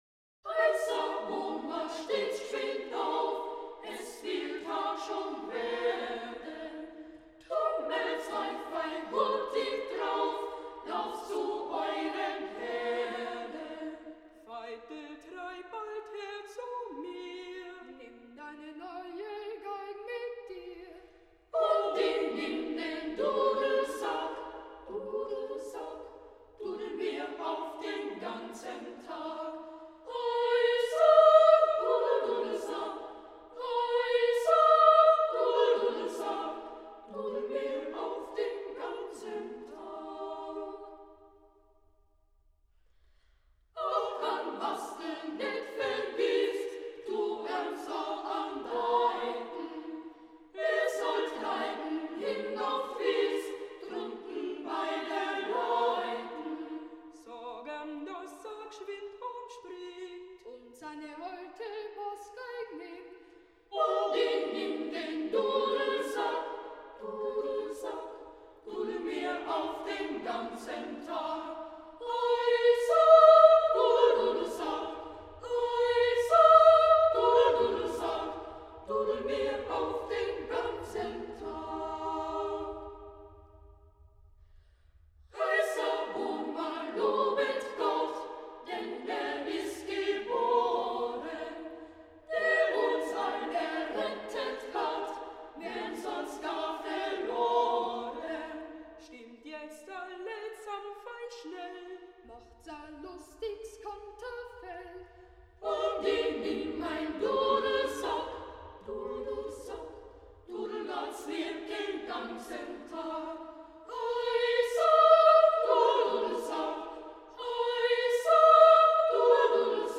ist ein Anglöckellied aus dem Pongau
s an Austrian shepherd carol